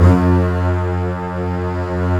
Index of /90_sSampleCDs/Roland LCDP13 String Sections/STR_Combos 2/CMB_MIDI Section